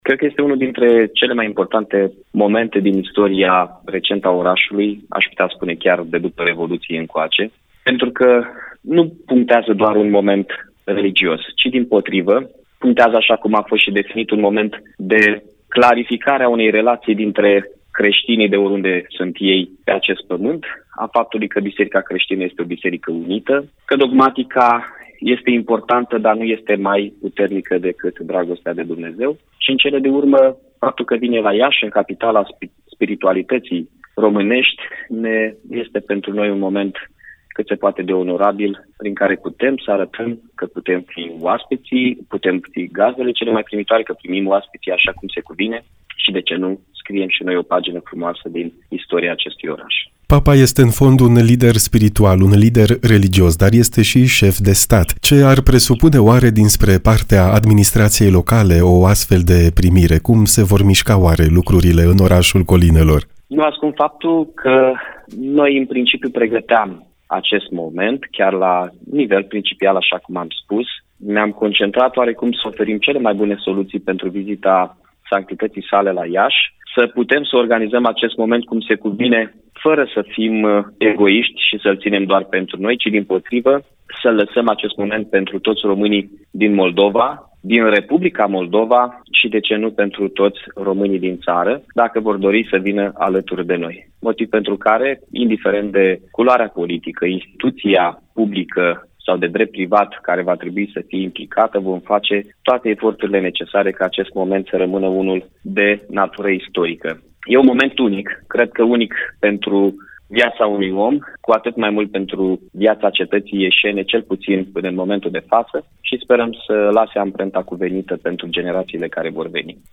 Mihai Chirica, primarul Iaşiului, spune, la Radio Maria, că "este unul dintre cele mai importante momente din istoria recentă a oraşului, pentru că nu punctează doar un moment religios, ci un moment de clarificare a unei relaţii dintre creştinii de oriunde ar fi, a faptului că dogmatica este importantă, dar nu este maiputernică decât dragostea de Dumnezeu.